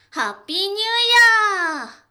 ボイス
女性